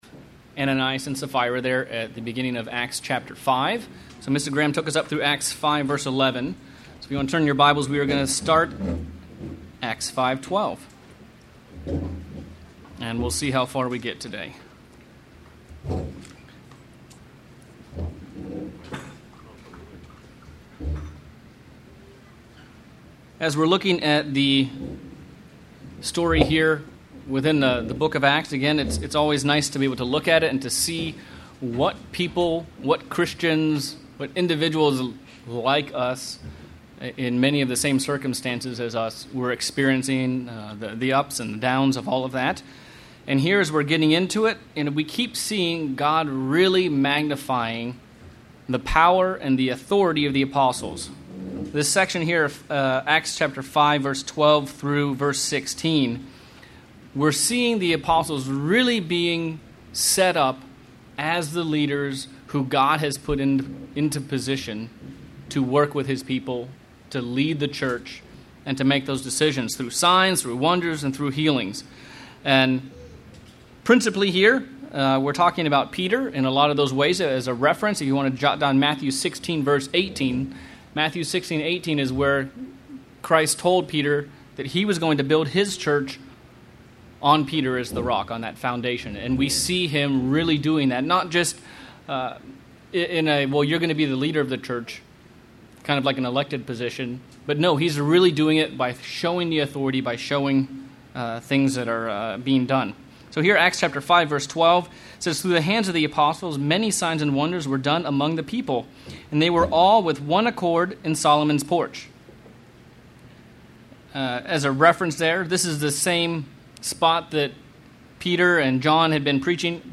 Bible Study: Acts of the Apostles - Chapter 5:11 - 6:7